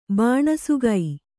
♪ bāṇasugai